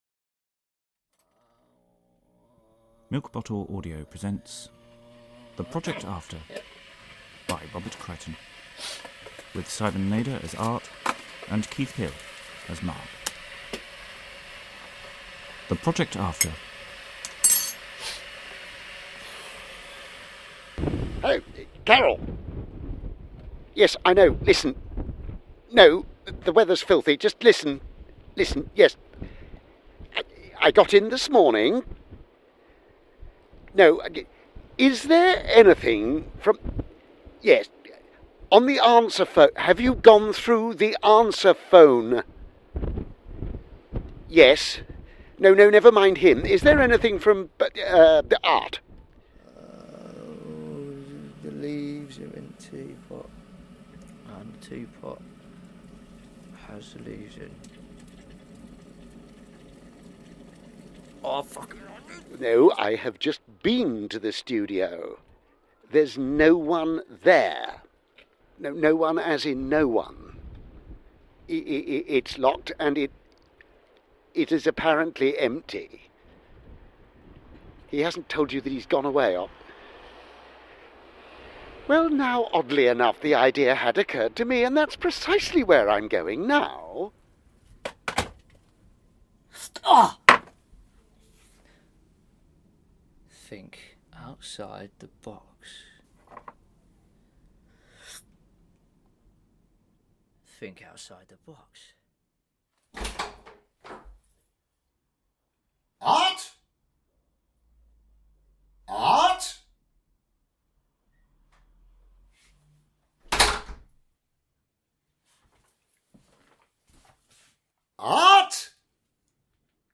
Audio adaptation of the stage play, with the original cast.